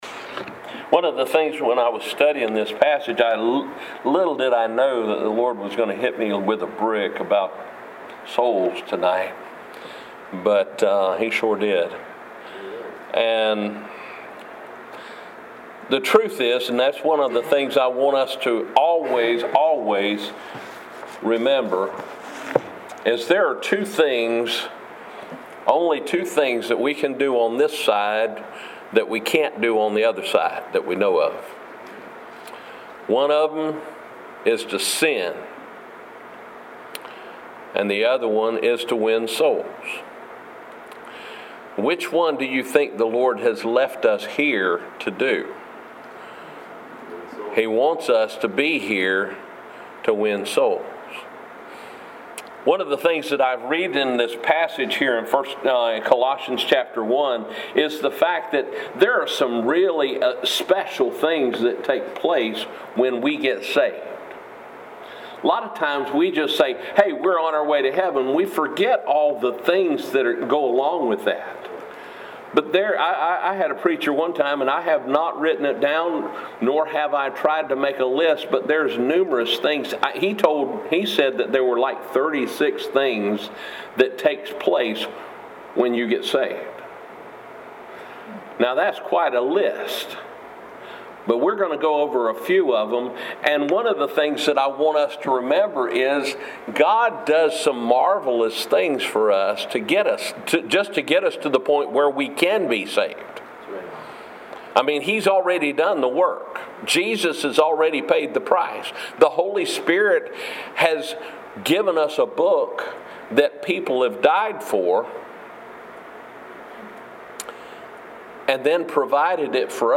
Sermons
3/1/2020 Morning Service